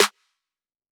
BB - Snare (Too Late).wav